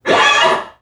NPC_Creatures_Vocalisations_Robothead [4].wav